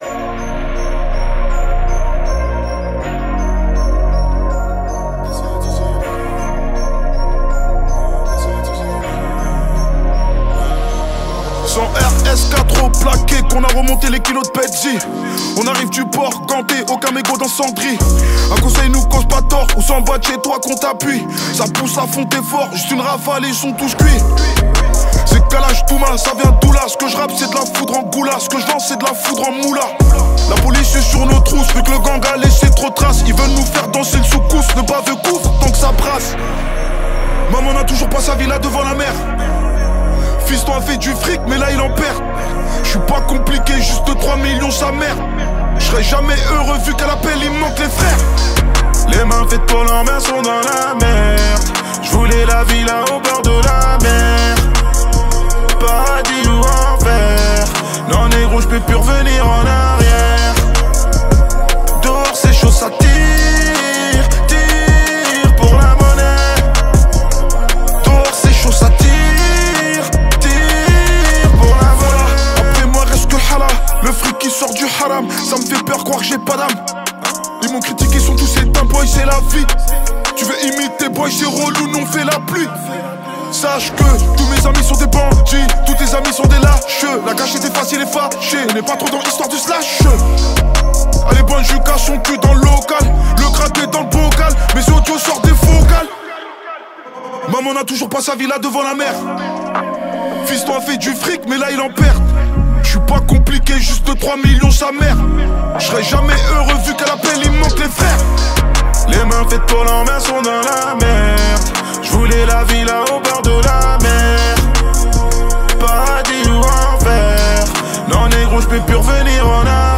raï, moroccan chaabi, algerian chaabi, moroccan pop, gnawa